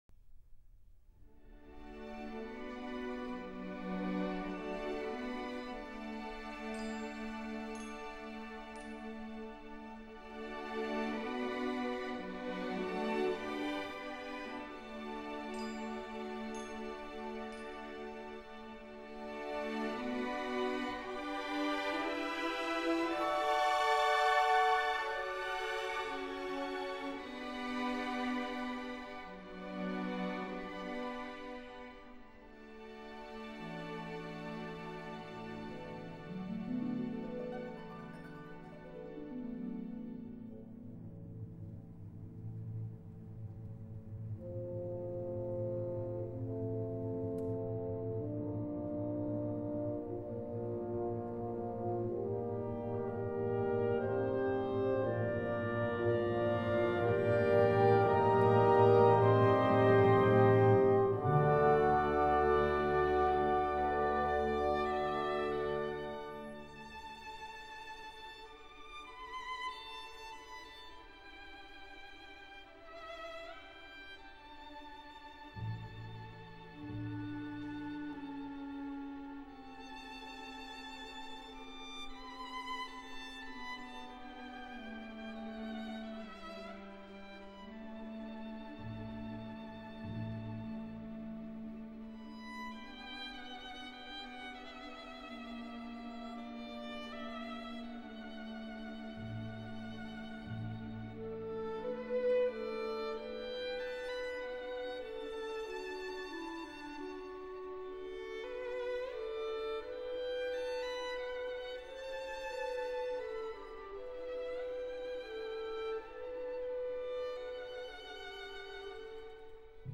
按照大型管弦乐团的器乐编制以及演奏要求改编出这套《音乐会组曲》